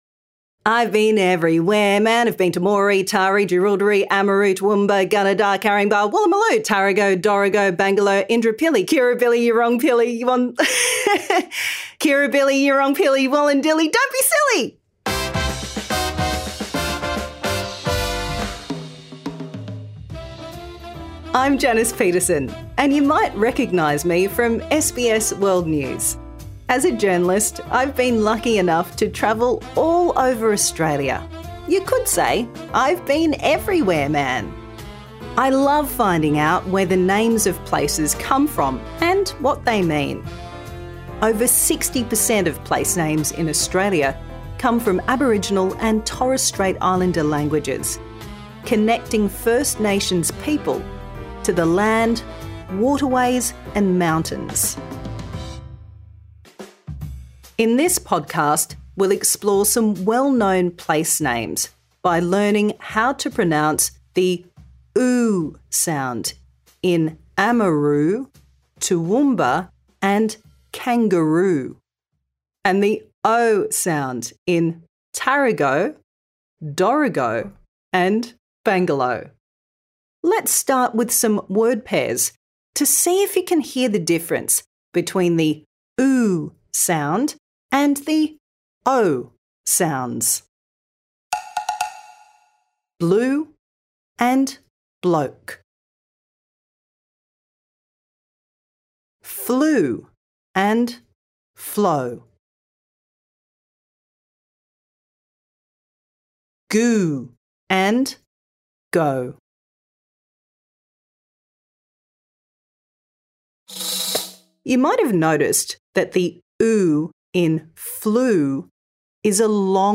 Learning objectives: Can pronounce /uː/ and /əʊ/ (flu and flow).
Improve your pronunciation | Season 2